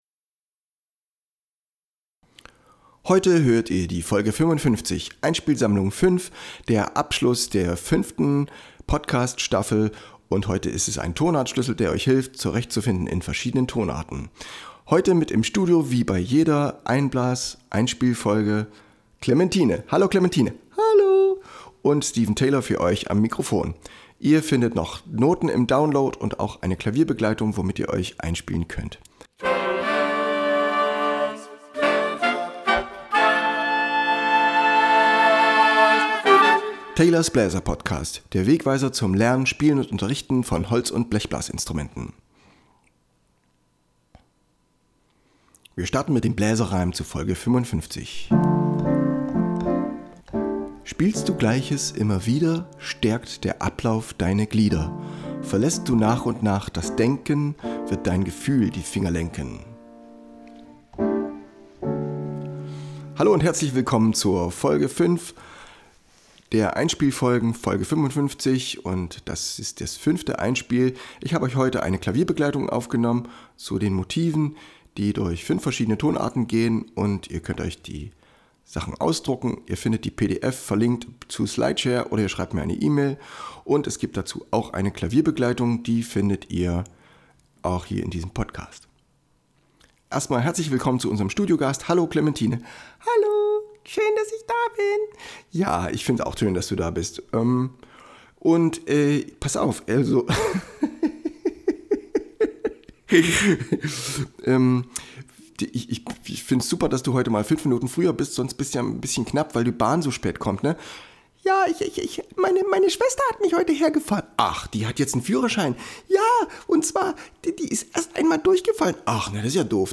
Beschreibung: Einspiel-Motive mit Begleit-Akkorden in 5 Tonarten für alle Instrumente.